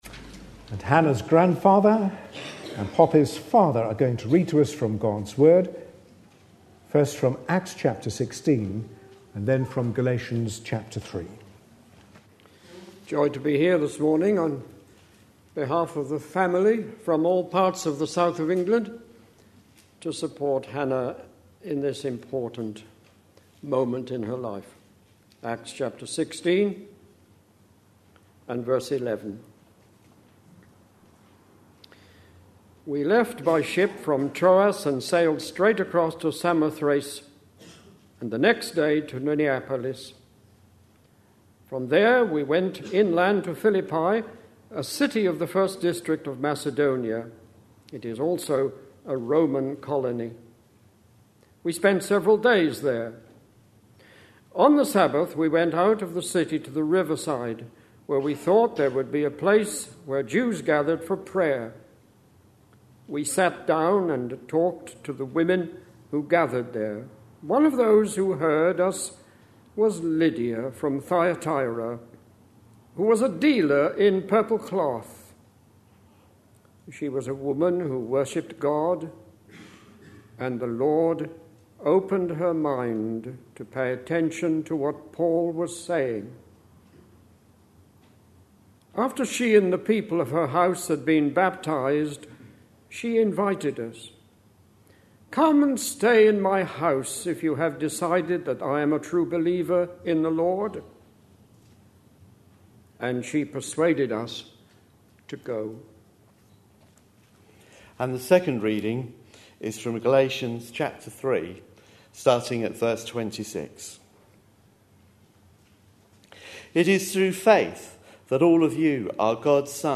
A sermon preached on 30th May, 2010, as part of our Acts series.
Acts 16:11-15 Listen online Details This service included baptisms; the readings are Acts 16:11-15 and Galatians 3:26-28 (also a reference to Revelation 3:20).